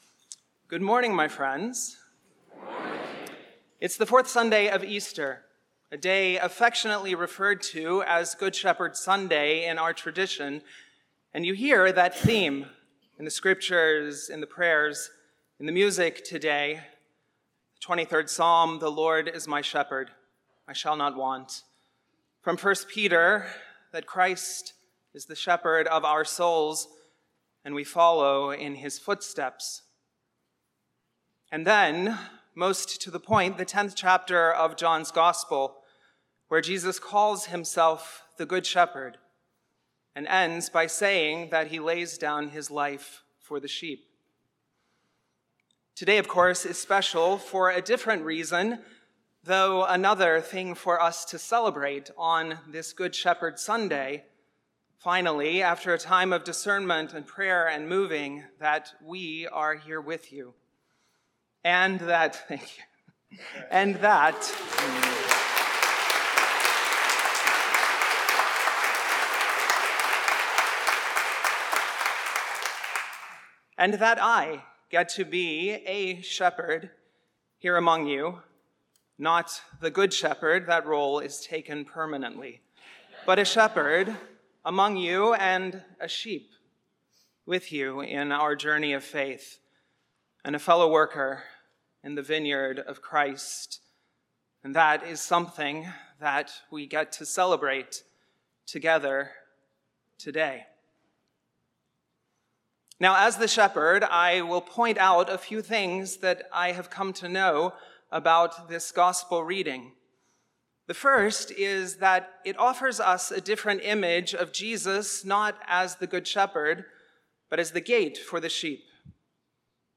St-Pauls-HEII-9a-Homily-26APR26.mp3